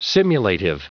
Prononciation du mot simulative en anglais (fichier audio)
Prononciation du mot : simulative